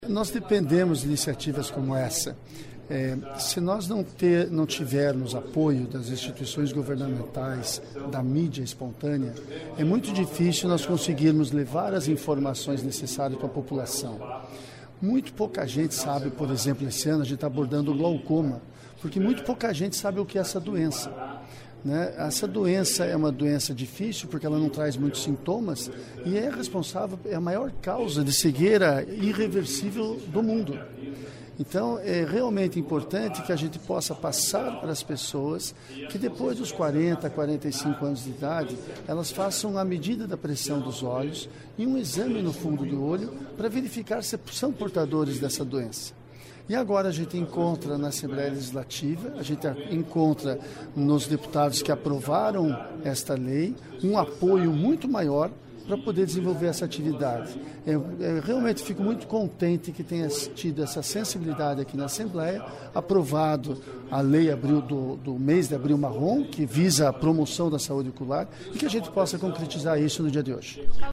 Ouça entrevista com o médico oftalmologista, referência no tema no paraná, sobre a iniciativa da lei que instituiu o "Abril marrom", mês de conscientização e de combate às doenças oculares.
(Sonora)